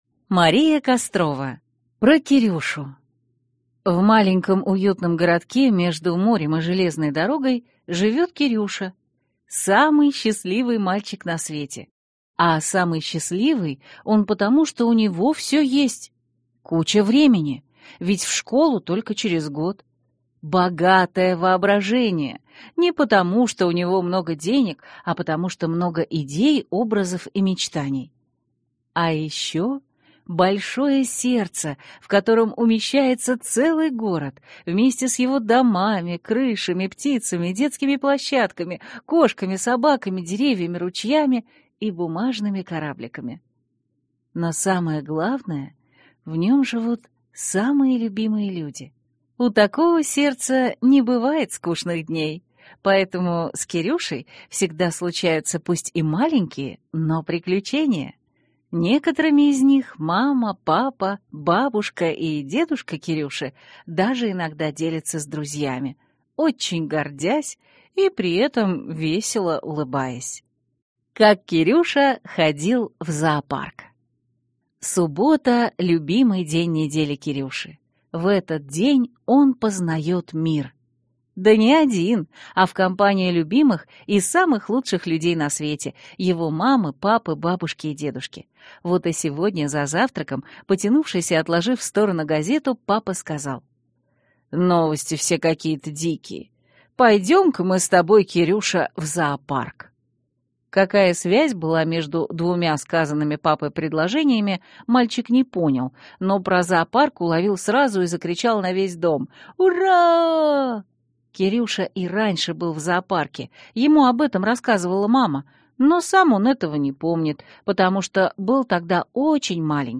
Аудиокнига Про Кирюшу | Библиотека аудиокниг
Прослушать и бесплатно скачать фрагмент аудиокниги